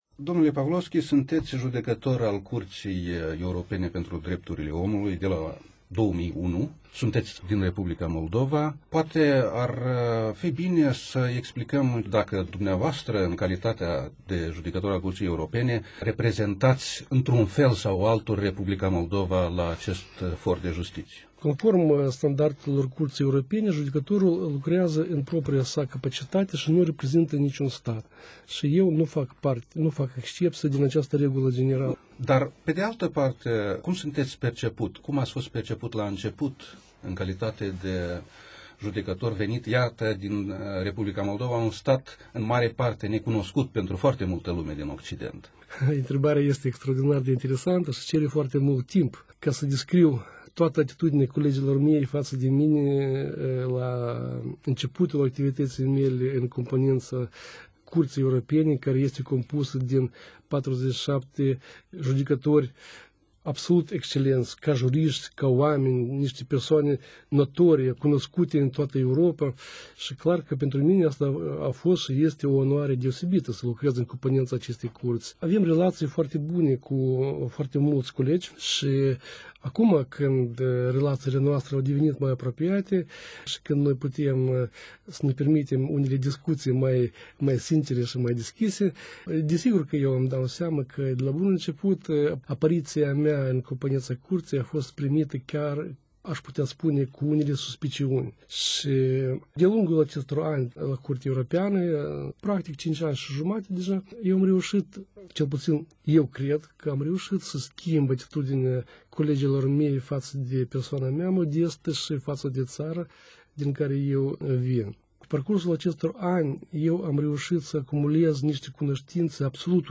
interviu_pavlovschi.wma